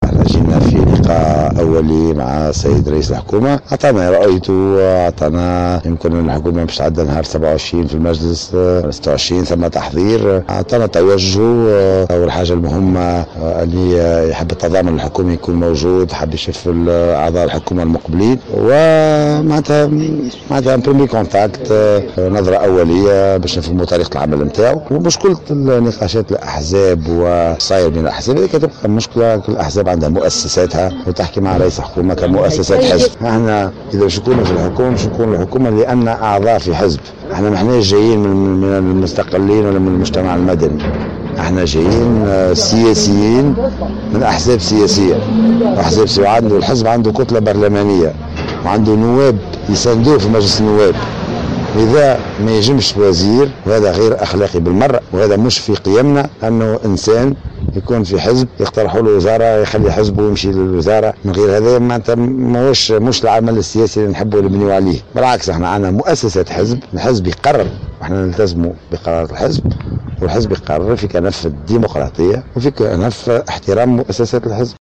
وأضاف الموخر في تصريح إعلامي على إثر لقاء رئيس الحكومة المكلف يوسف الشاهد مساء اليوم بعدد من أعضاء فريقه الحكومي أنه من غير الأخلاقي أن ينفصل عن الحزب بمجرد حصوله على حقيبة وزارية، نافيا أن يكون رئيس الحزب ياسين ابراهيم غير راض على الوزارات التي أسندت للحزب.